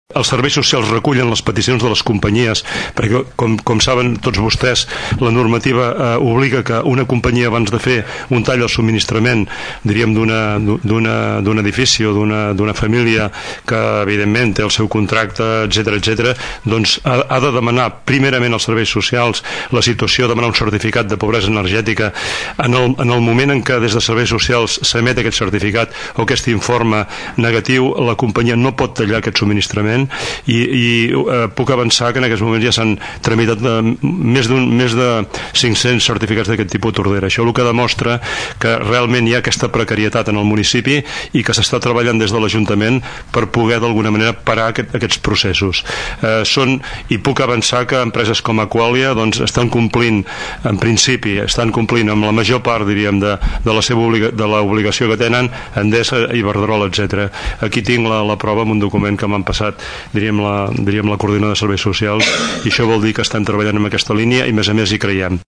Àngel Pous, regidor de Serveis Socials, va anunciar al plenari que ja fa temps que s’estan aplicant mesures per combatre la pobresa energètica i que, fins ara, a Tordera s’han tramitat més de 500 certificats.